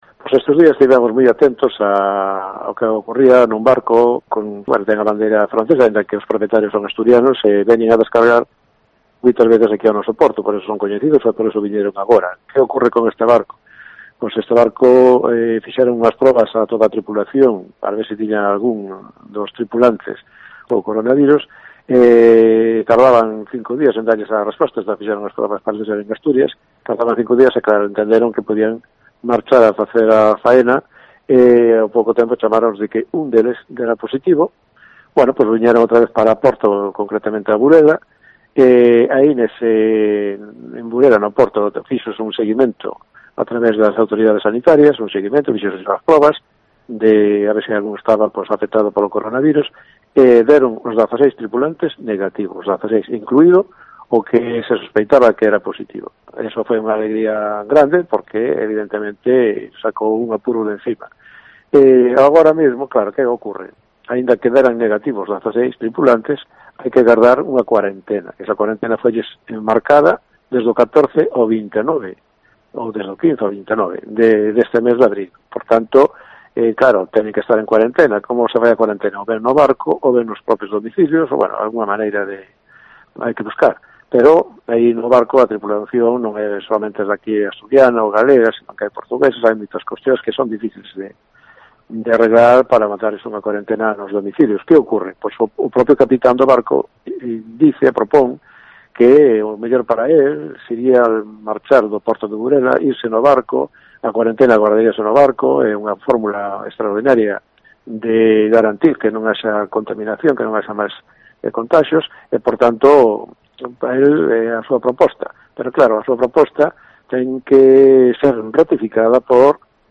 Declaraciones de ALFREDO LLANO, alcalde de Burela, sobre el barco retenido en puerto